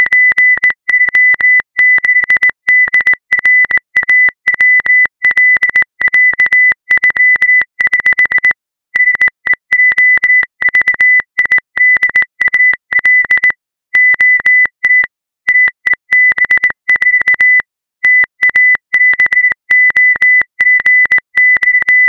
描述：摩尔斯电码信息
Tag: 莫尔斯码 莫尔斯电码